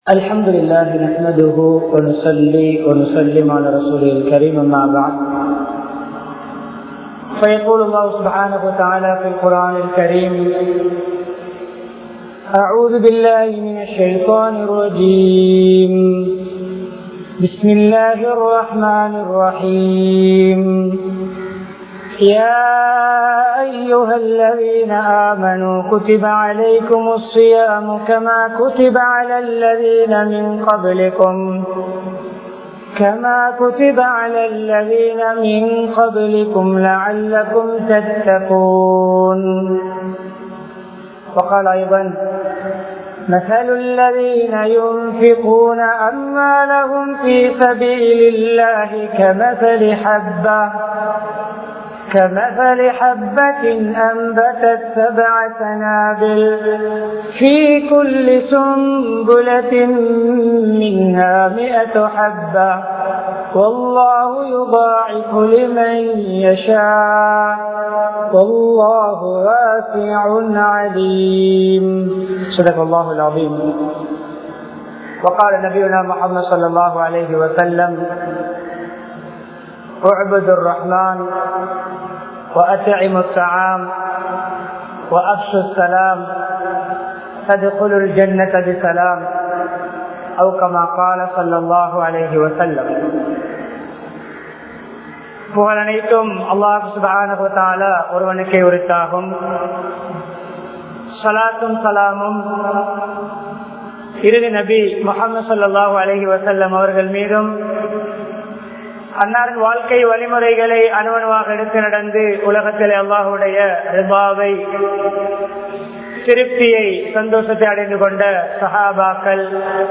ரமழான் | Audio Bayans | All Ceylon Muslim Youth Community | Addalaichenai